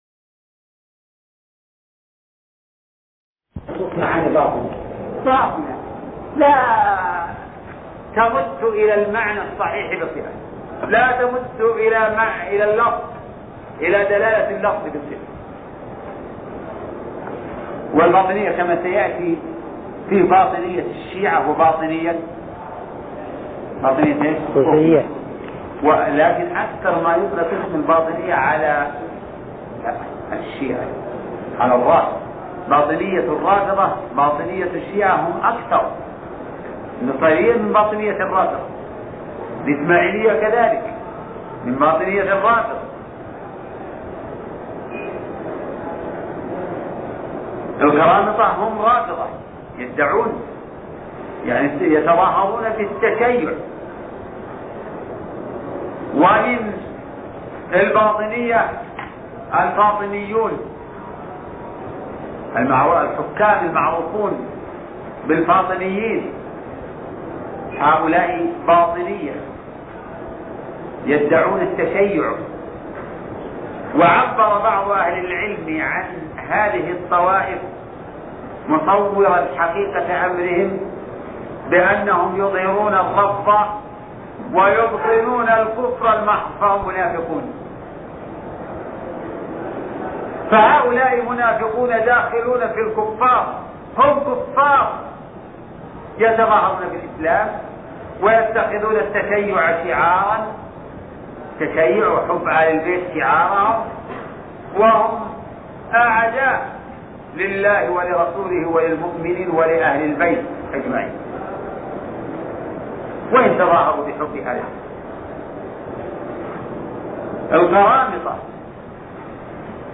الدرس (5) شرح التدمرية - الشيخ عبد الرحمن بن ناصر البراك